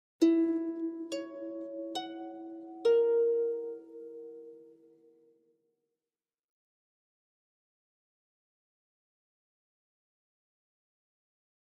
Harp, Very Slow Reverberant Arpeggio, Type 4